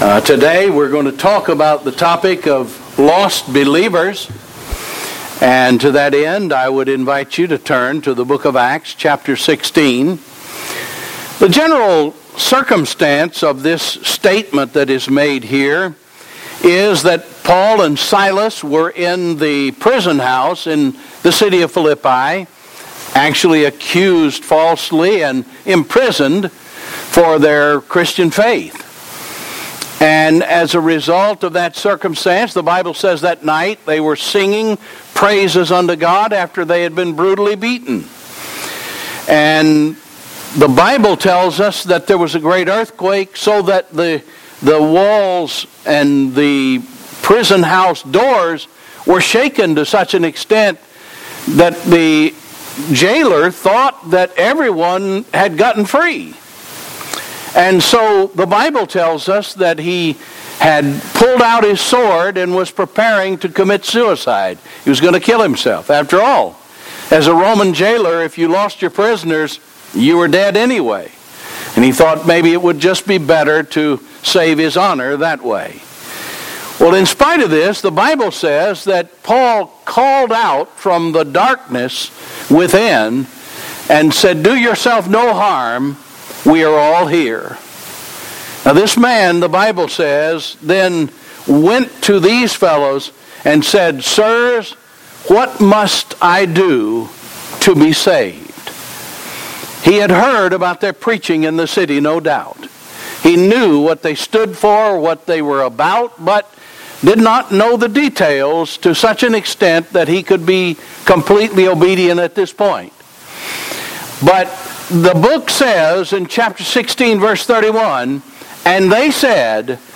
Damnation ← Newer Sermon Older Sermon →